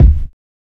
KICK SICK.wav